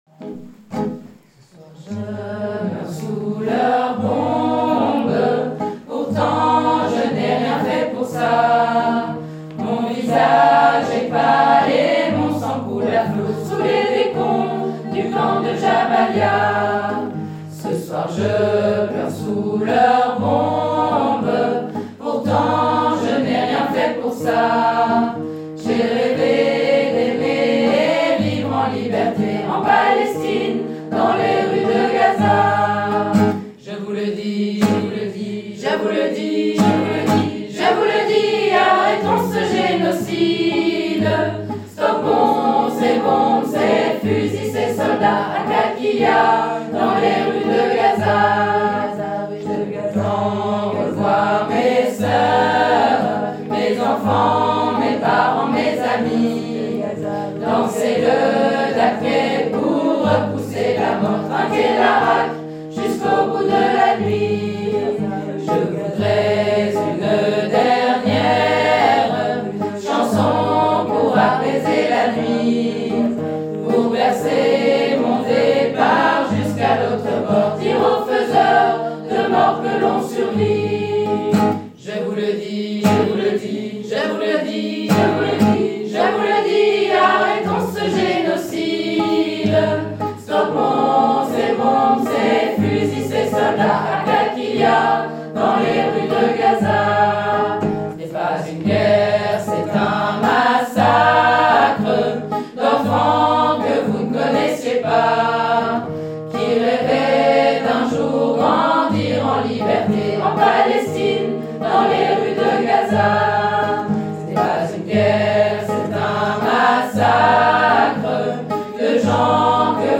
Tutti. Calais 04-2025
------ Cette version se chante à 2 voix, lead et basse, une voix haute venant s'ajouter en fin de couplet et de refrain.